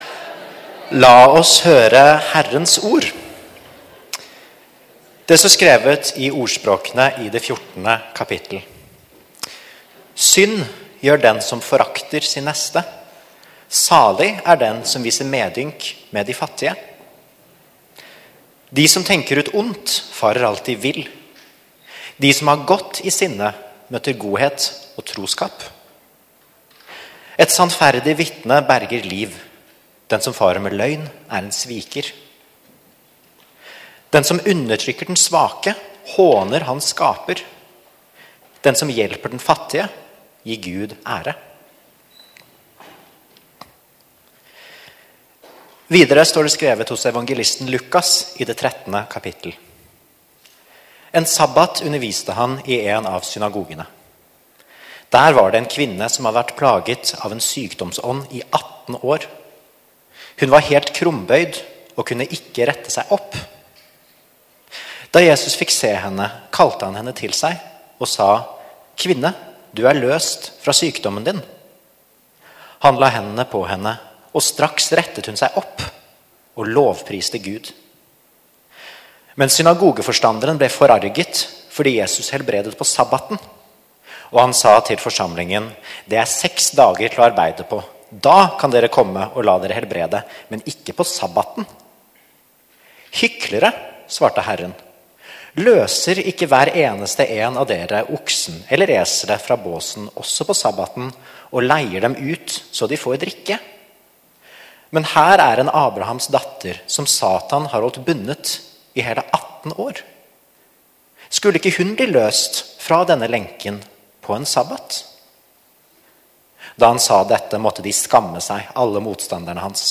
1 Gudstjeneste 26. januar 2025, 4. søndag i åpenbaringstiden 21:46